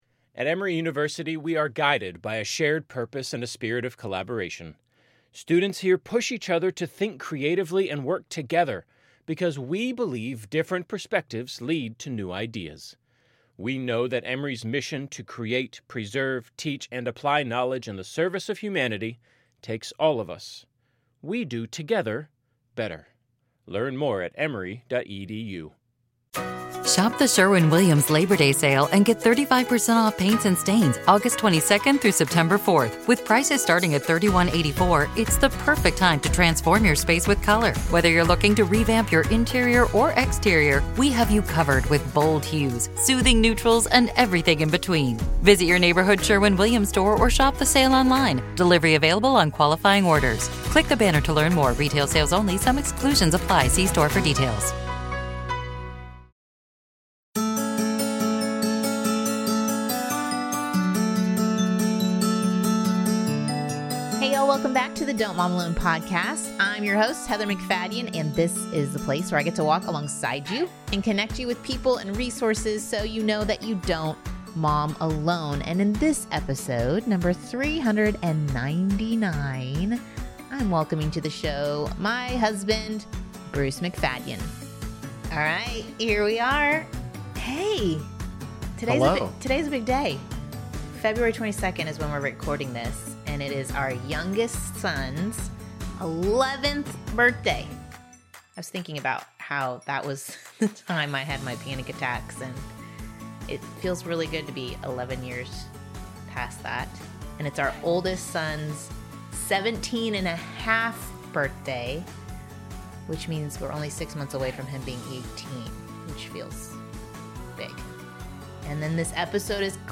Q + A